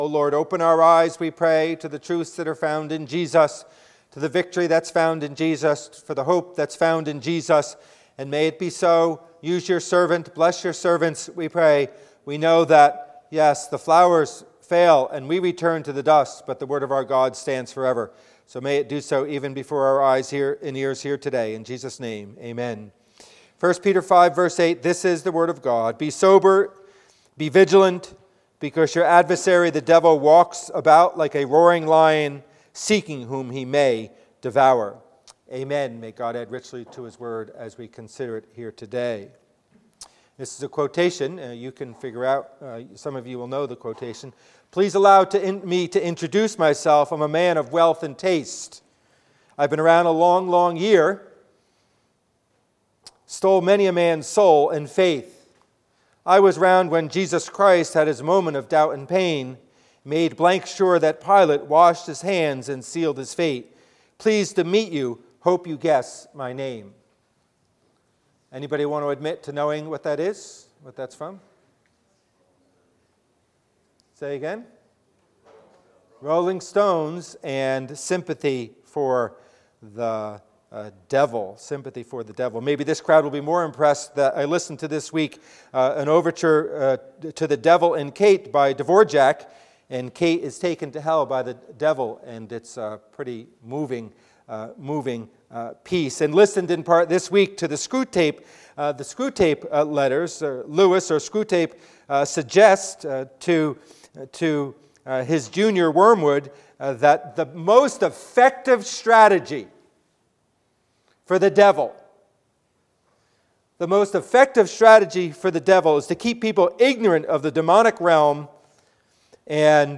Passage: 1 Peter 5:8 Service Type: Worship Service